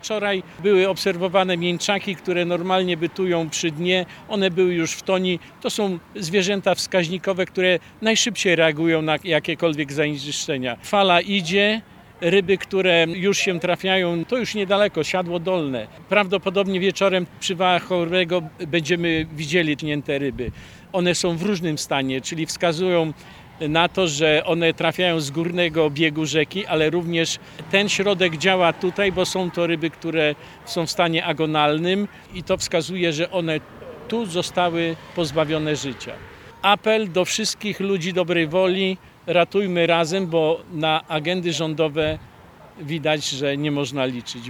podczas dzisiejszej konferencji prasowej